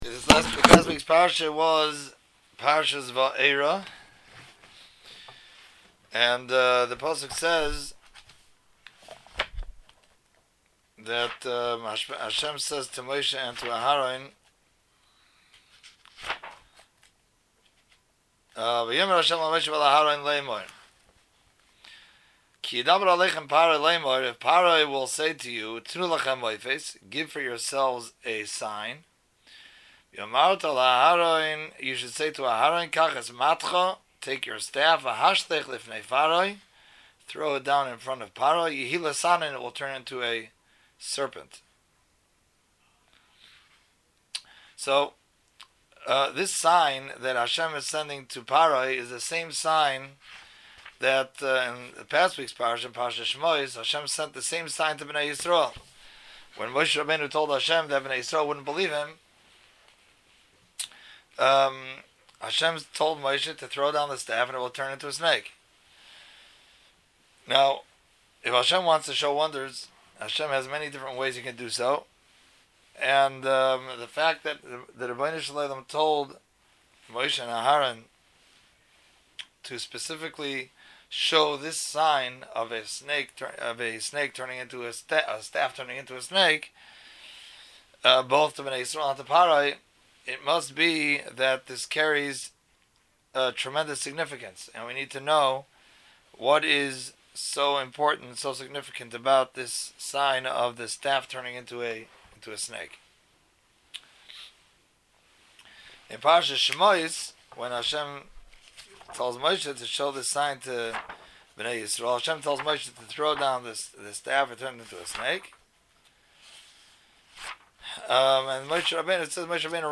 Vaeira Drasha- The Snake is Hashem’s Staff